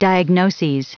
Prononciation du mot diagnoses en anglais (fichier audio)
Prononciation du mot : diagnoses